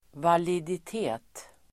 Uttal: [validit'e:t]